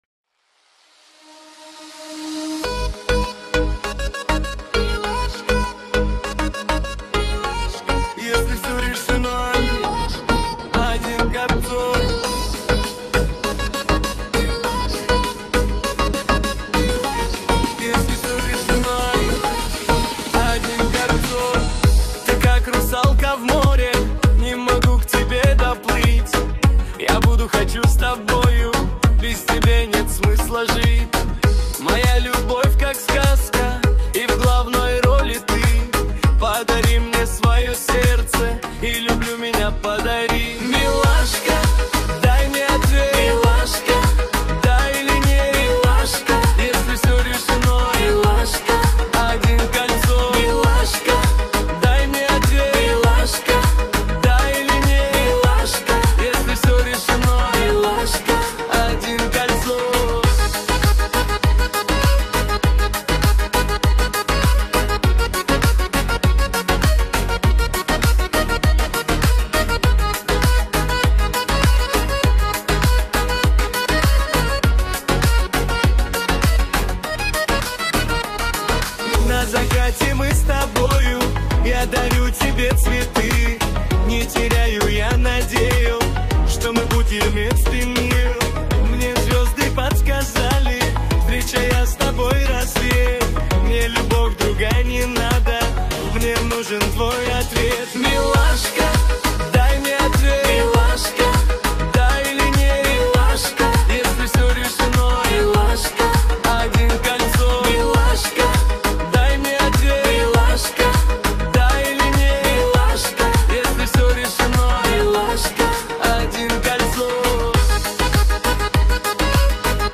Качество: 320 kbps, stereo
Ремиксы, 2026